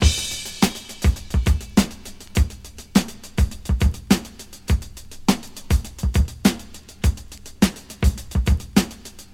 Free drum beat - kick tuned to the F note. Loudest frequency: 3902Hz
• 103 Bpm High Quality Drum Loop Sample D# Key.wav
103-bpm-high-quality-drum-loop-sample-d-sharp-key-NSi.wav